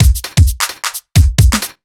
OTG_Kit 3_HeavySwing_130-C.wav